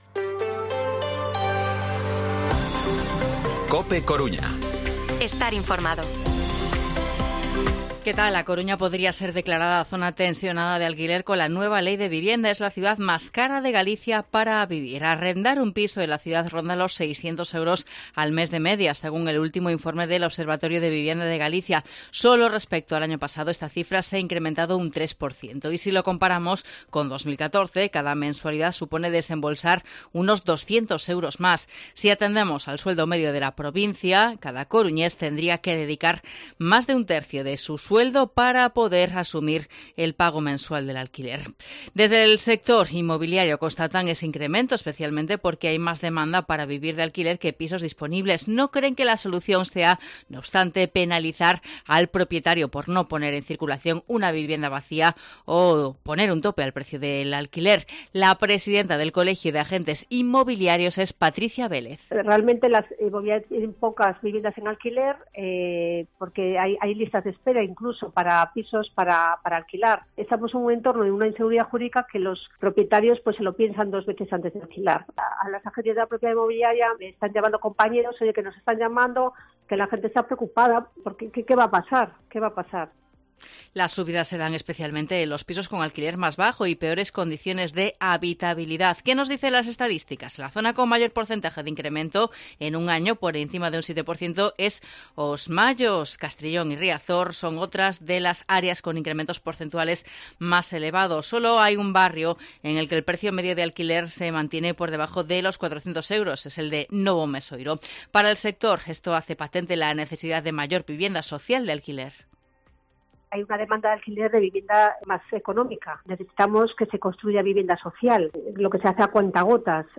Informativo Mediodía COPE Coruña martes, 18 de abril de 2023 14:20-14:30